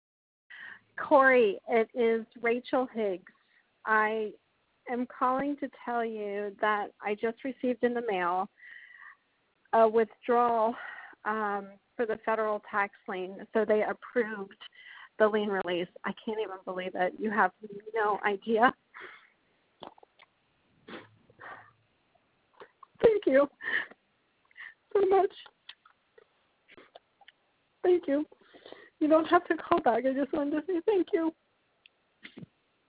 Listen to a recent review from one of our clients:
silver-tax-group-voice-testimonial.wav